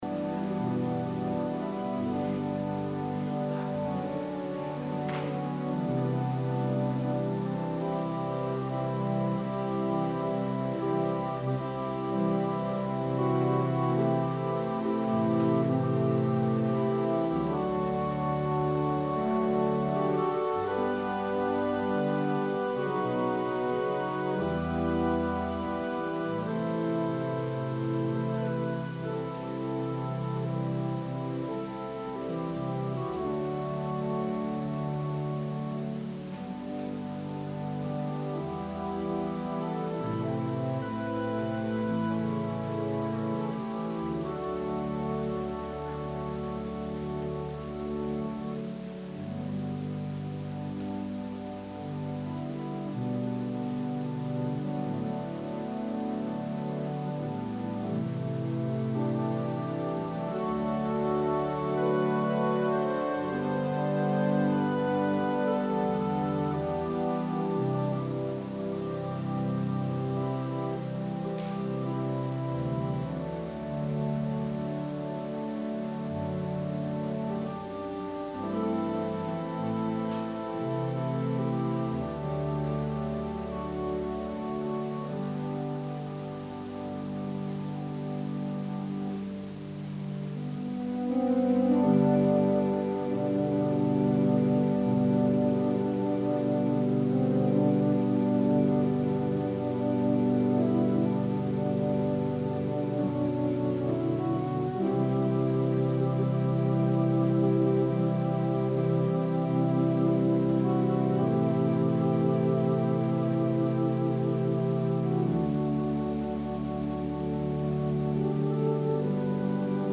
Sermon – September 27, 2020